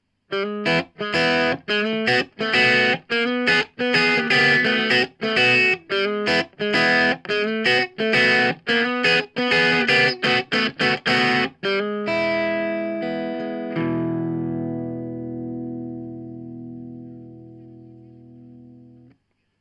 Twangy.mp3